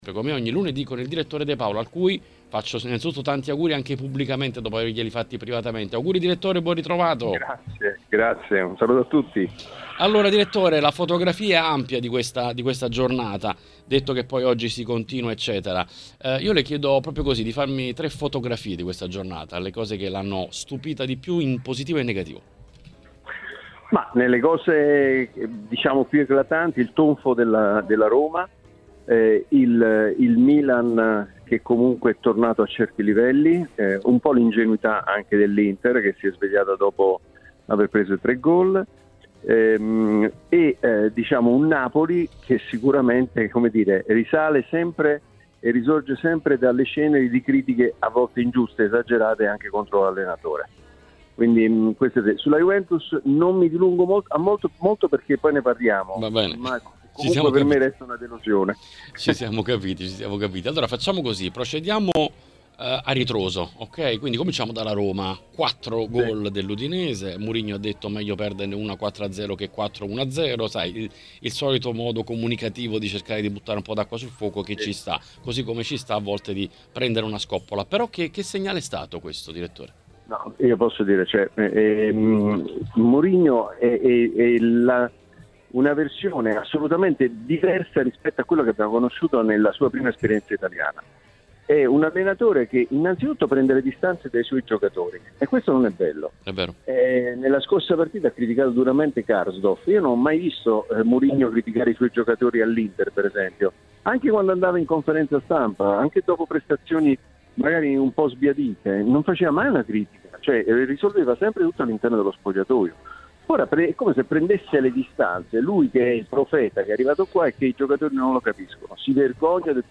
ai microfoni di TMW Radio